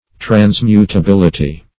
Transmutability \Trans*mu`ta*bil"i*ty\, n.
transmutability.mp3